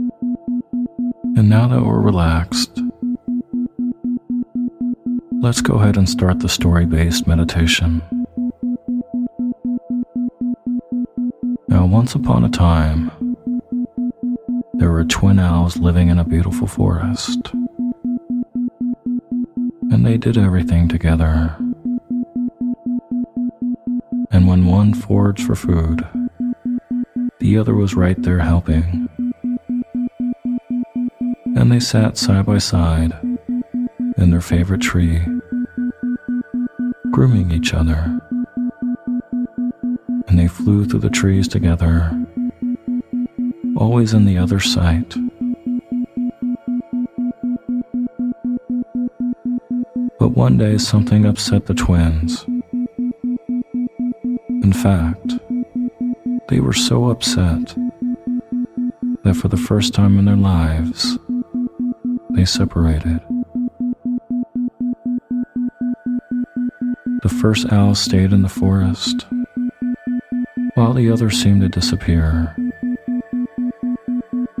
Story Based Meditation “The Two Owls or Twin Owls” With Isochronic Tones